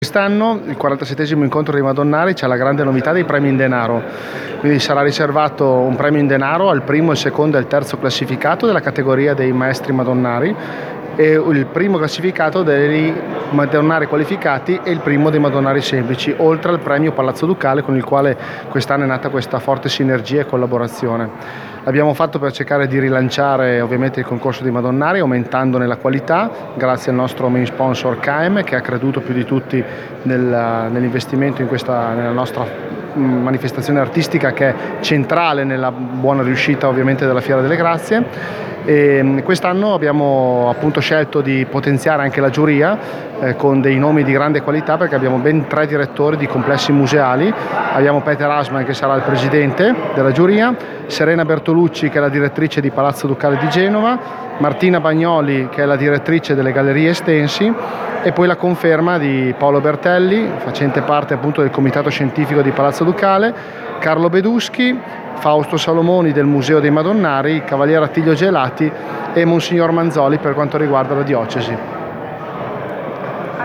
Novità di quest’anno l’introduzione di premi in denaro per i primi classificati delle varie categorie in gara, come spiegato da Federico Longhi: